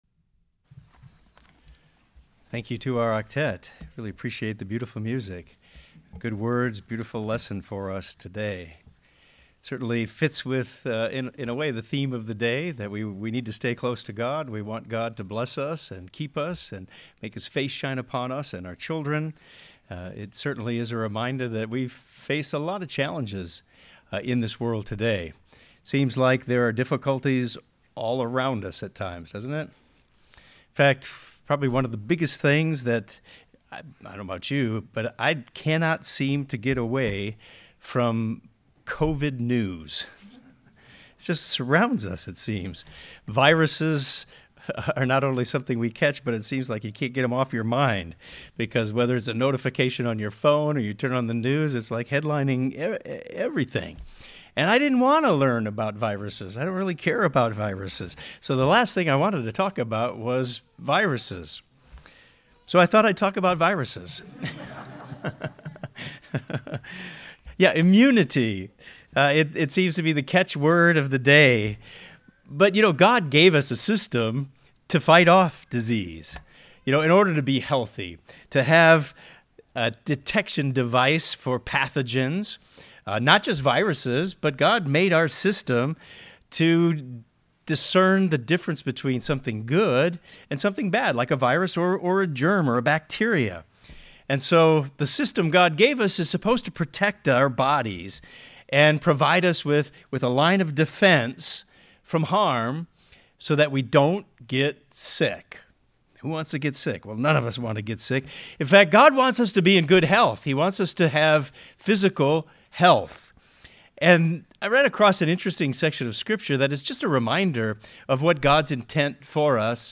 This sermon discusses how we can use the "present distress" to help us ward off spiritual disease.